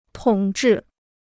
统治 (tǒng zhì): to rule (a country); to govern.